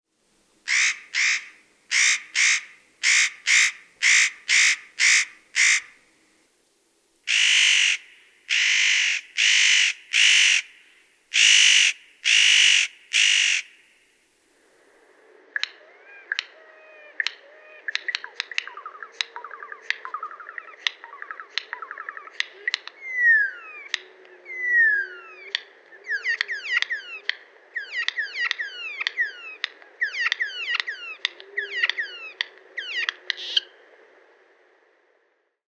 Pähkinähakki
pähkinähakki.mp3